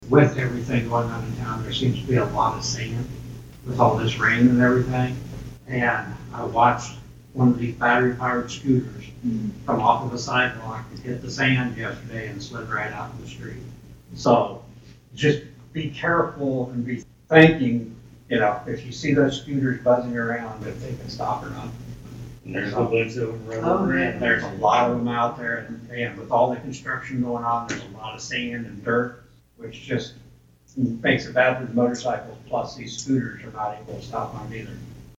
(Atlantic, Iowa) – Several matters were brought-up during the Council Committee reports portion of the Atlantic City Council's meeting, Wednesday evening (July 2nd), at City Hall in Atlantic. Councilman Shawn Sarsfield urged persons on scooters to be cautious on city streets, where there are construction projects underway.